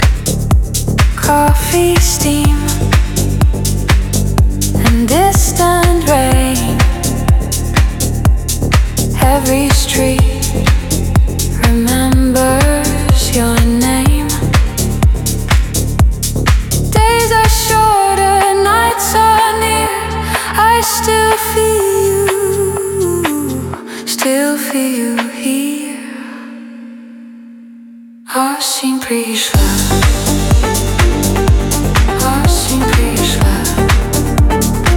House Dance
Жанр: Танцевальные / Хаус / Украинские